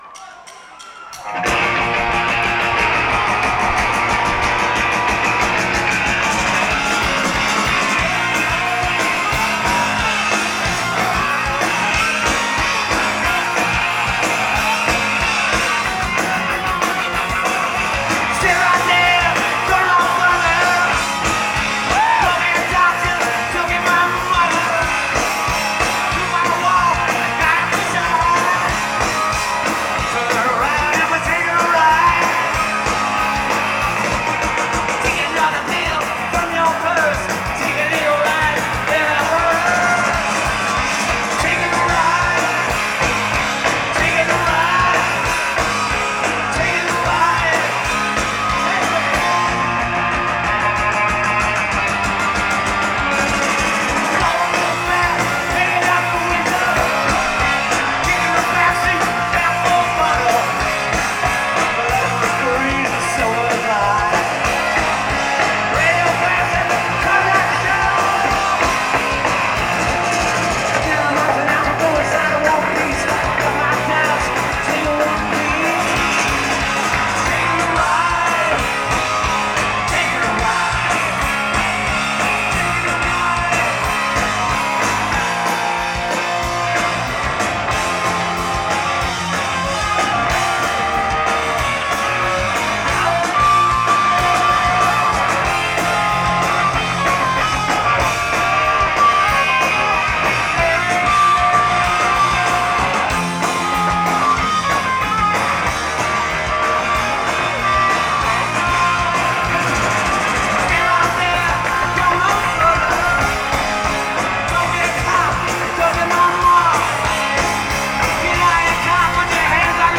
Live at Riot Fest
At Fort York, Toronto, Ontario, Canada